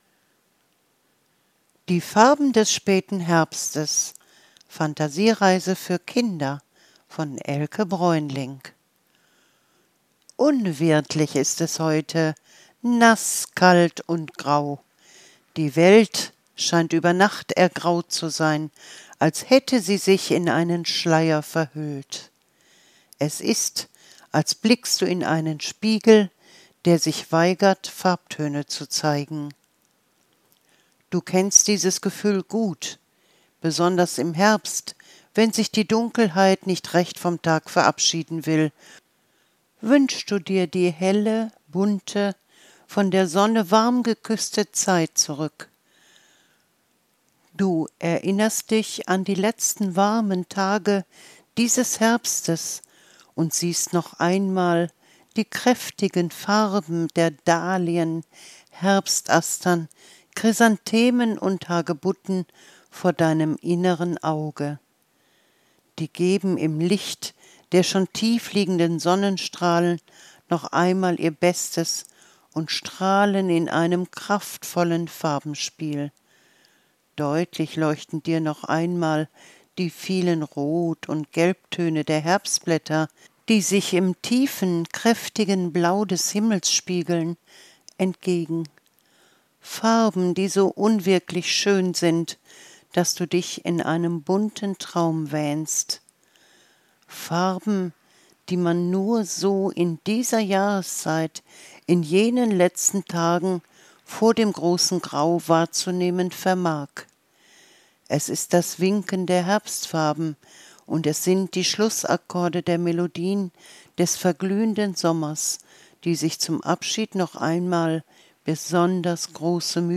Fantasiereise zum späten Herbst – Das Herbstbunt wandelt sich langsam in Wintergrau